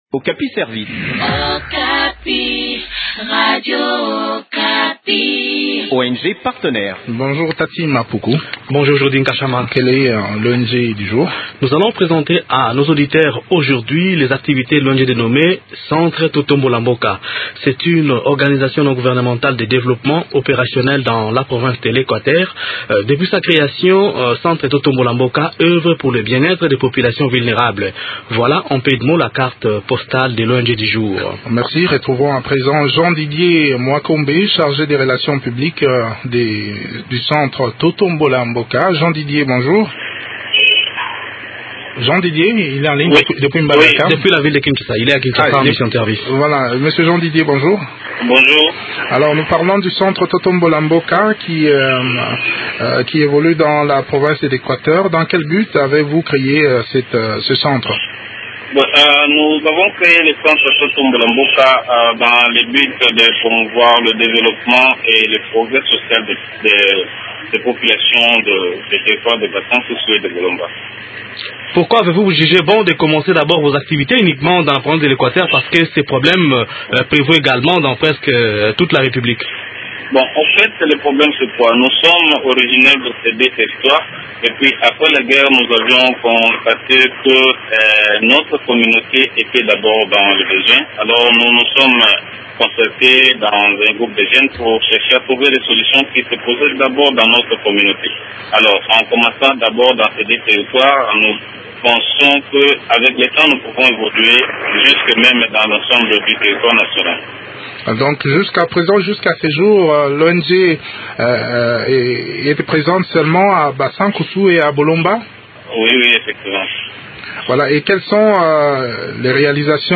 Le Centre Totombola Mboka est un organisme non étatique de développement actif dans la province de l’Equateur. Depuis sa création dans les années 1990, cette ONGD oeuvre pour le bien-être de la population vulnérable. Découvrons les activités de cette structure dans cet entretien